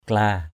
/kla:/ 1.